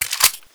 Index of /server/sound/weapons/dmg_colt1911